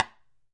木材与硬物敲打
描述：木材与硬物敲打。